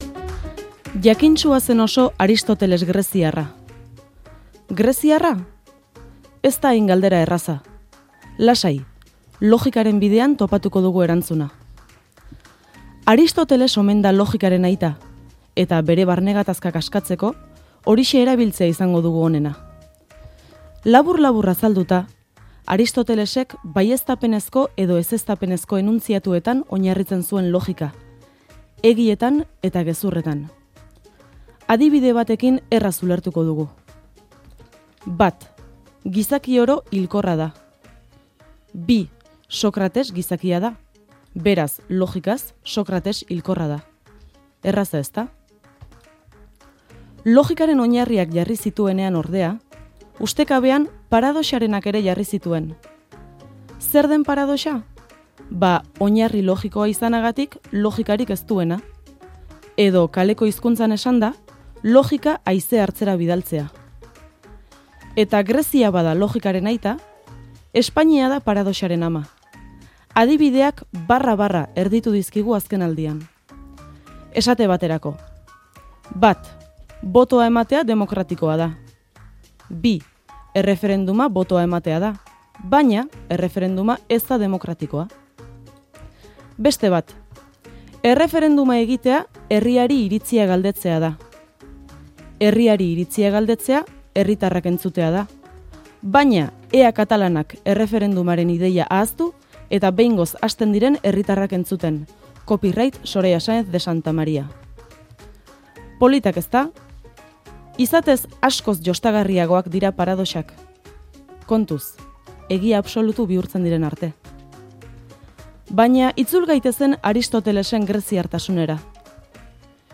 logika eta paradoxak azpimarratu ditu Diada eguneko bere irrati-iritzi tartean.